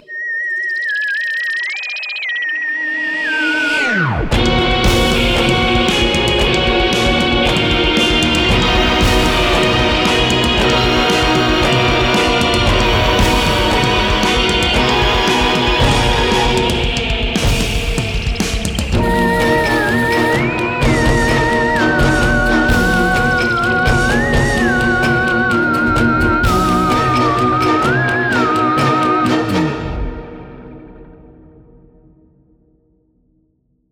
BA_30_epic_music.aif